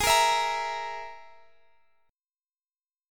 Absus2#5 Chord
Listen to Absus2#5 strummed